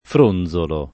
fronzolo [ fr 1 n z olo ]